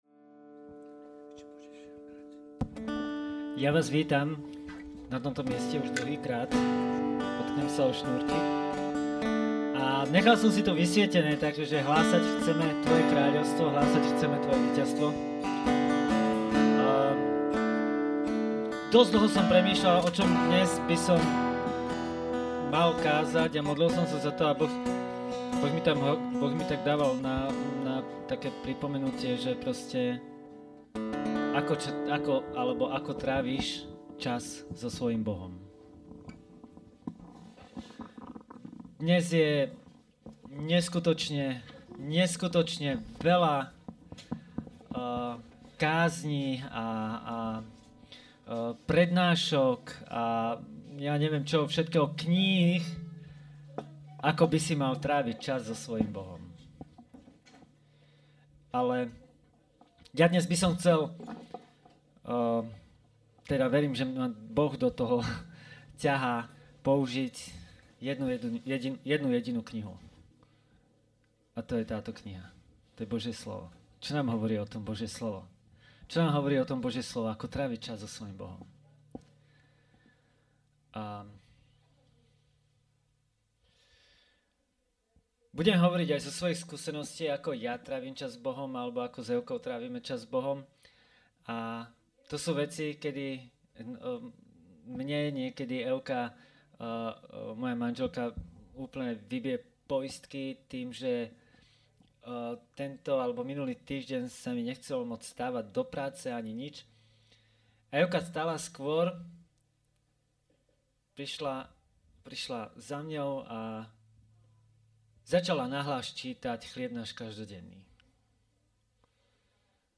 Tentokrát audiozáznam z nedele – slovo na tému AKO TRÁVIŤ ČAS S BOHOM.
Kázeň týždňa